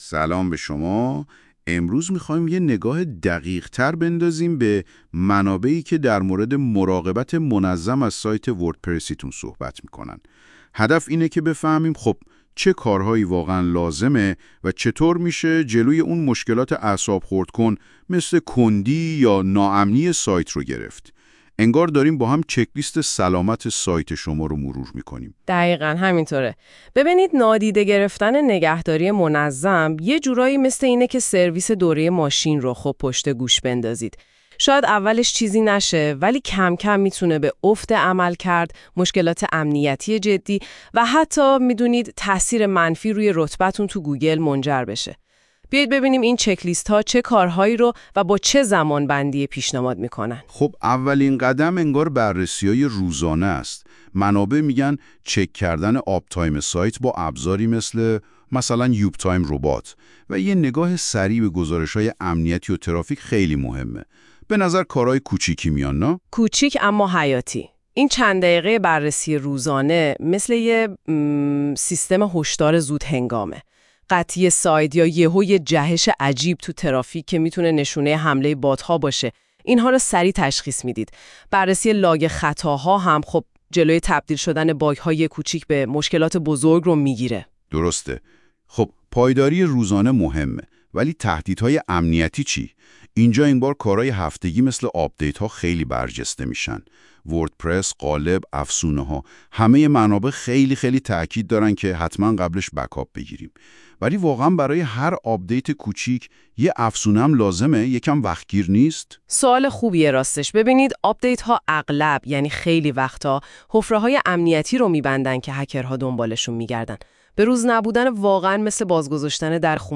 قبل از شروع مقاله ، اگه حوصله یا فرصت مطالعه این مقاله رو ندارید ، پیشنهاد می کنیم پادکست صوتی زیر که با ابزار هوش مصنوعی (گوگل notebooklm ) به زبان فارسی توسط تیم پروان وب تولید شده است ، گوش کنید.